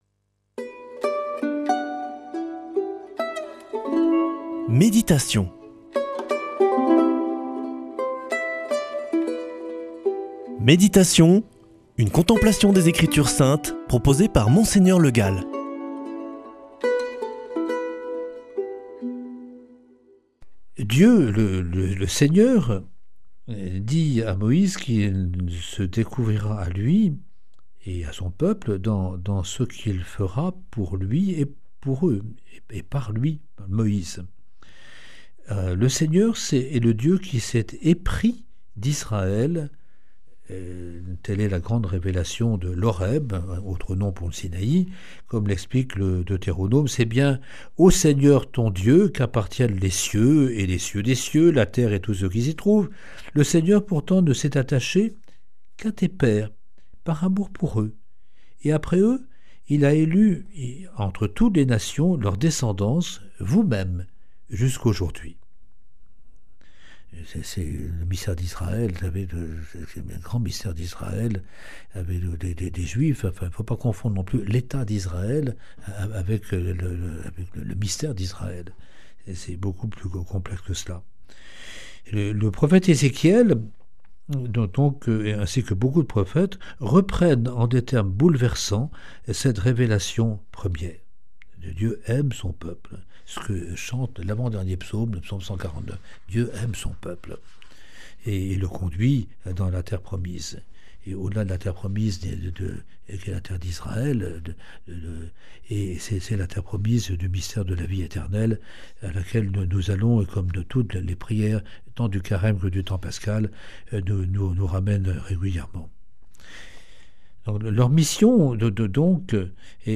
lundi 2 juin 2025 Méditation avec Monseigneur Le Gall Durée 7 min
Présentateur